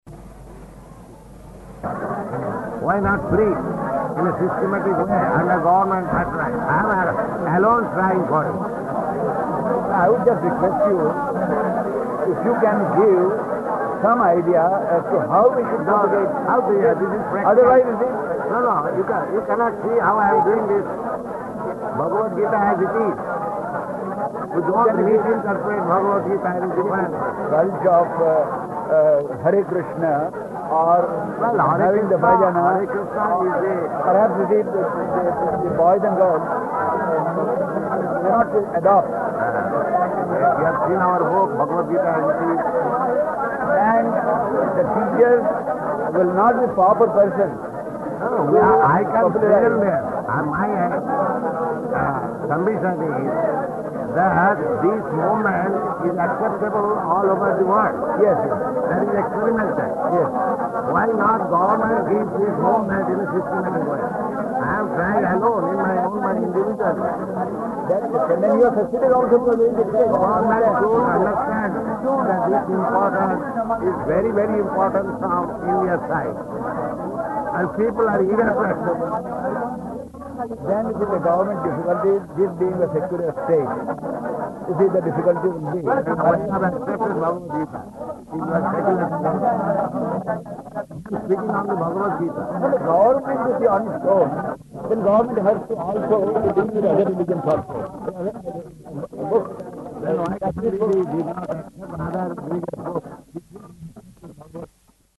Conversation [Partially Recorded]
Location: Surat
Audio file: 701226R1-SURAT.mp3